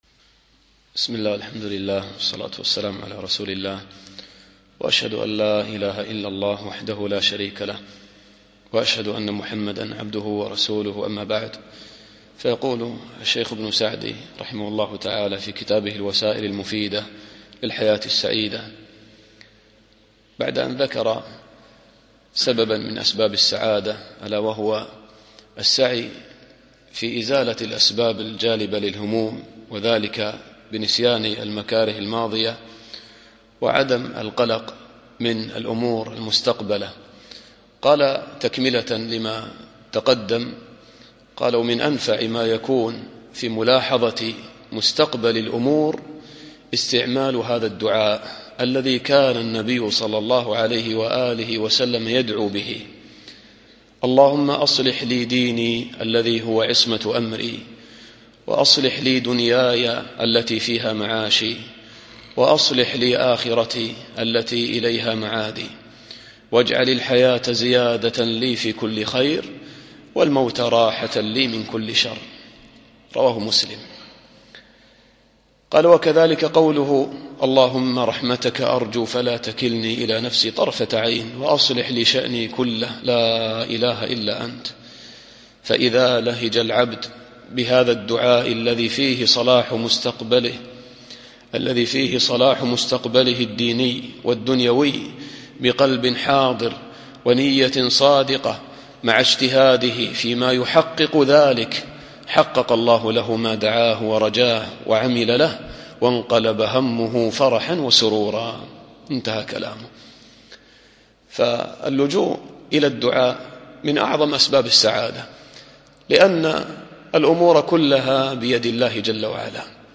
الدرس الحادي عشر